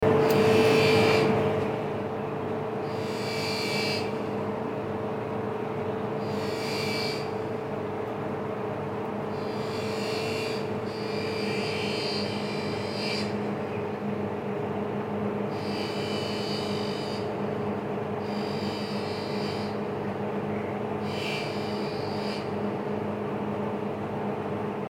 Ambiente dentro de una fábrica: Efectos de sonido industria
Categoría: Efectos de Sonido
Perfecto para añadir realismo y profundidad a escenas industriales, simulaciones, diseño de sonido ambiental urbano o cualquier situación que requiera la presencia sonora de maquinaria, procesos y la actividad característica de un entorno fabril.
Tipo: sound_effect
Ambiente dentro de una Fabrica.mp3